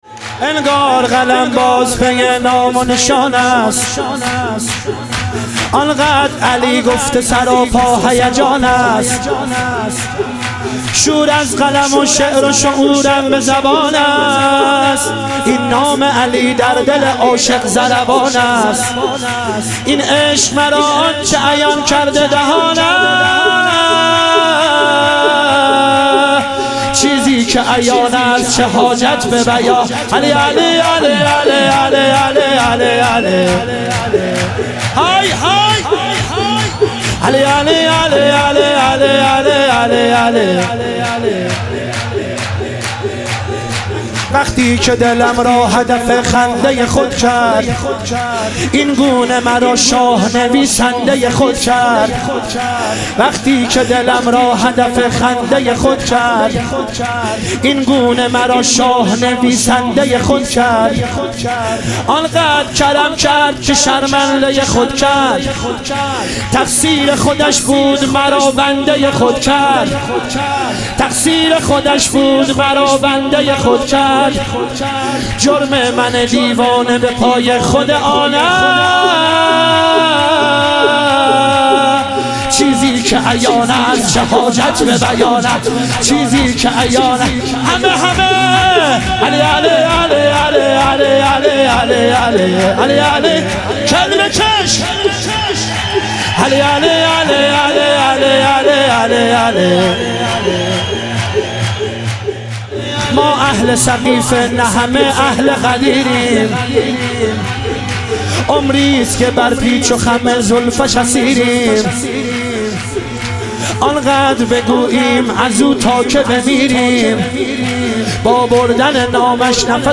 عید سعید غدیر خم - شور